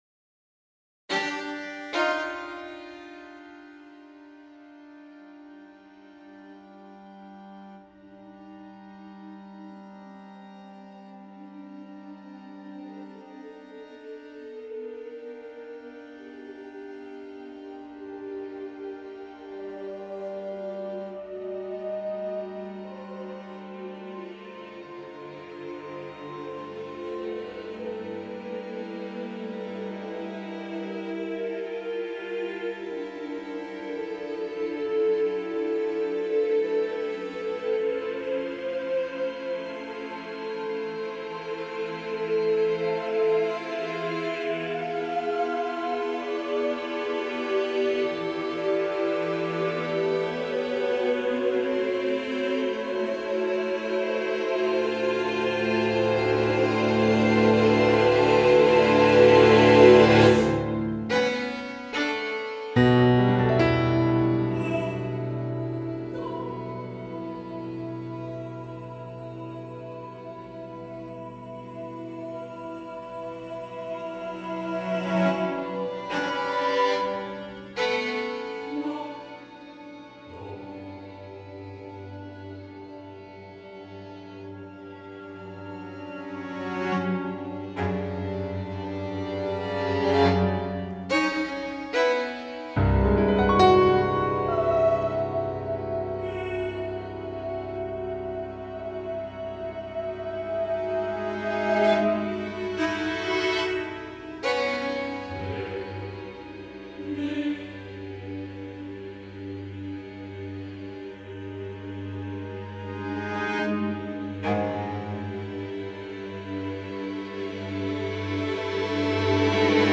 a choral and chamber music composition in nine movements